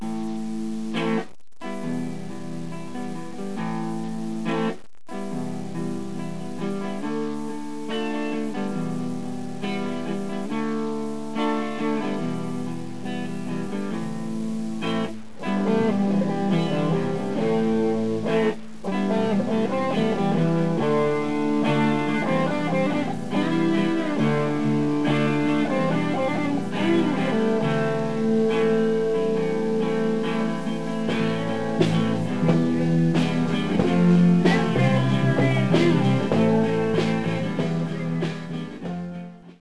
Cette introduction est plus lente que le reste de la chanson et sa grille d'accords ne se retrouve pas ailleurs.
LAm MIm | LAm MIm | REm MIm | REm MIm
Une première guitare joue deux fois ce cycle en accord et une deuxième guitare au son saturé joue la tablature ci-dessous sur le deuxième cycle. Après avoir jouer ces deux cycle, il y a une mesure de transition (LAm) pour amener le rythme de la chanson.